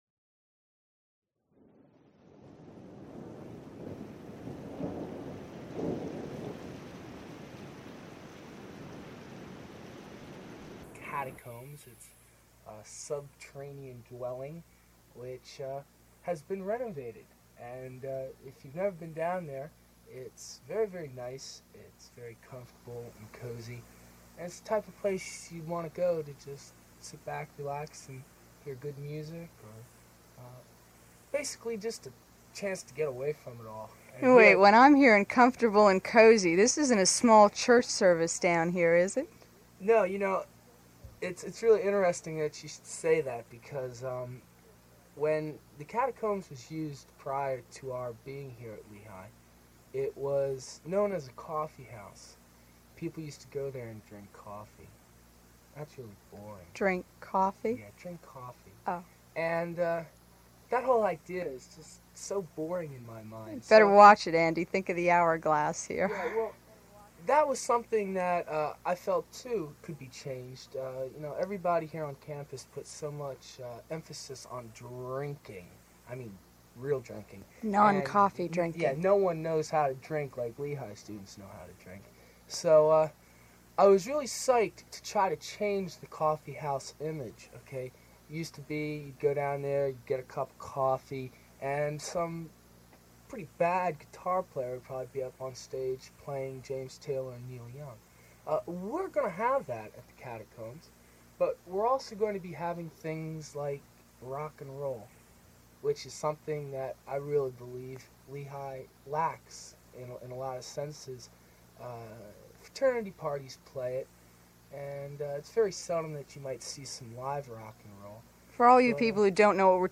WLVR announcement.mp3